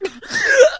hgasp3.ogg